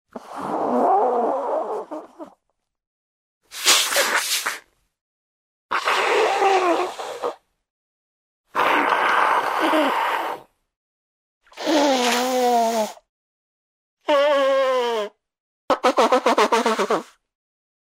Звуки шмыганья носом